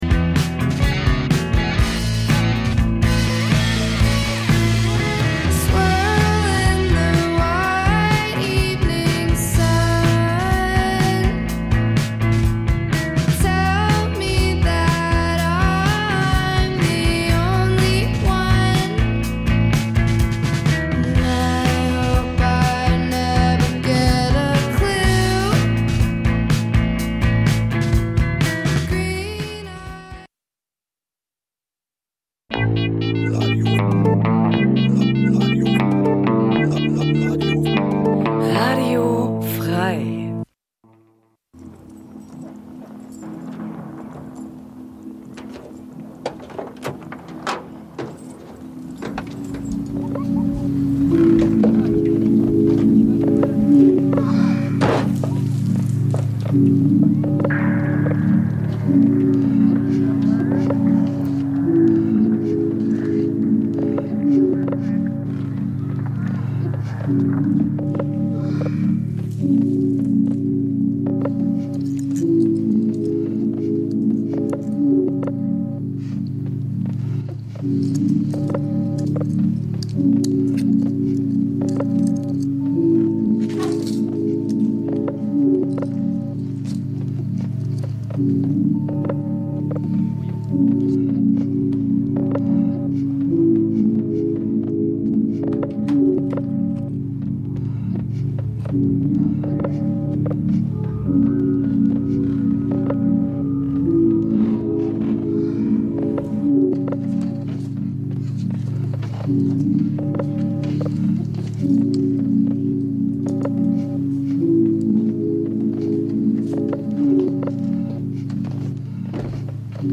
Ambient-Musik, oftmals als Fahrstuhlmusik, Hintergrundgedudel oder Kaufhausmusik diskreditiert, er�ffnet uns, bei genauerem Hinblick, eine neue Form des musikalischen Erlebnisses. Die meist ruhigen und getragenen elektronischen Kl�nge, der entschleunigte Charakter dieser Musikrichtung, erfordern ein �bewusstes Sich-Einlassen� auf die Musik, einen quasi meditativen Akt, der als musikalische Alternative zur modernen Leistungsgesellschaft gesehen werden kann.